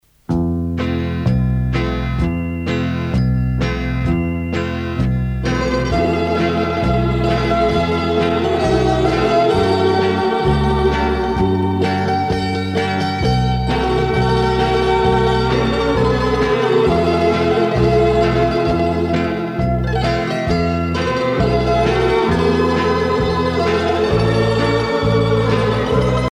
danse : sirtaki
Pièce musicale éditée